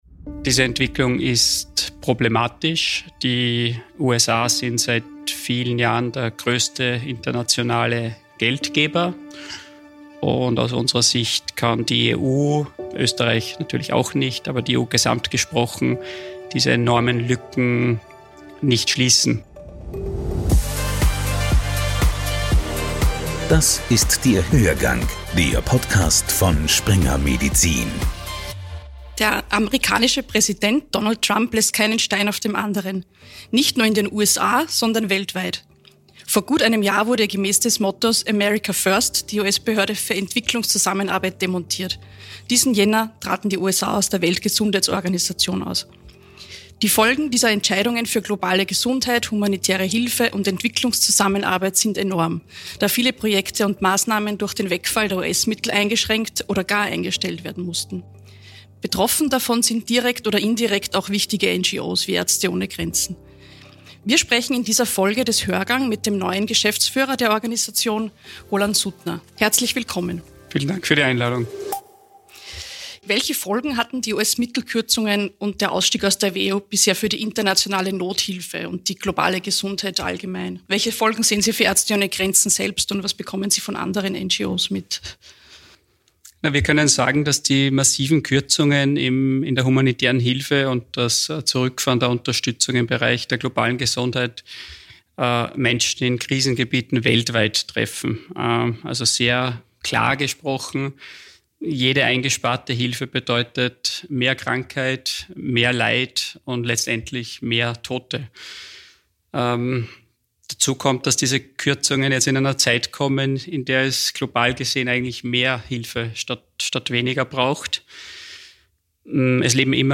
Ein Gespräch über politische Entscheidungen mit tödlichen Nebenwirkungen.